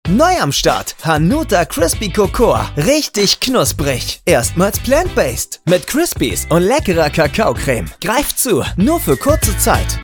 Sprachproben
Demo